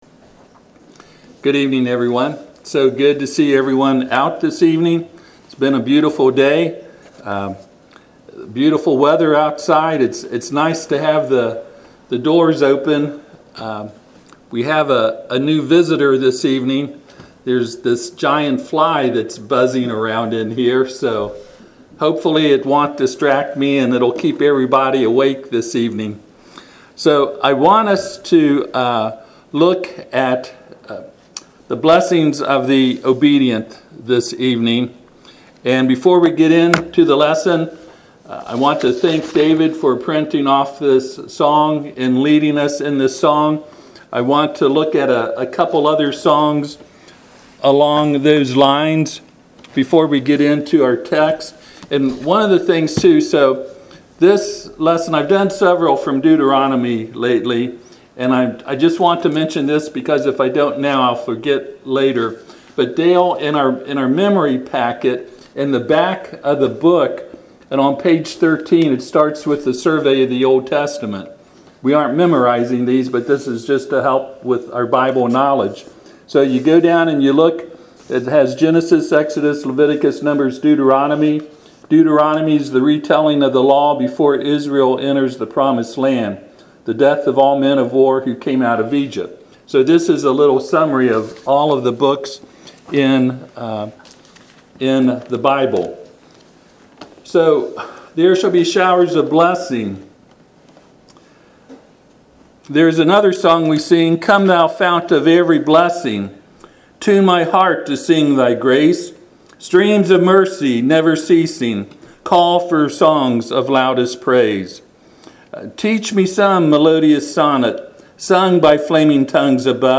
Passage: Deuteronomy 28:1-14 Service Type: Sunday PM